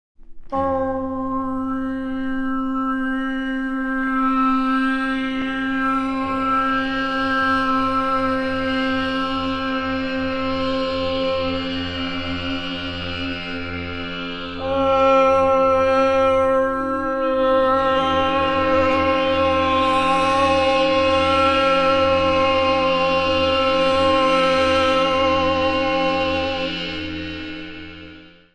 Cri-poème symphonique